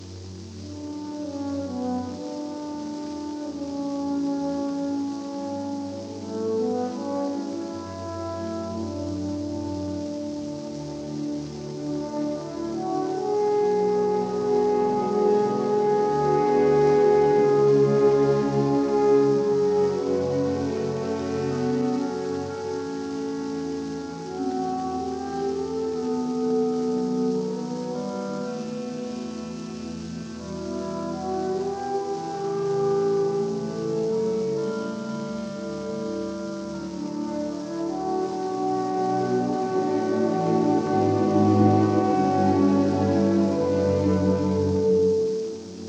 full frequency range recording